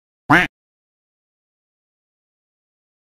دانلود صدای اردک 2 از ساعد نیوز با لینک مستقیم و کیفیت بالا
جلوه های صوتی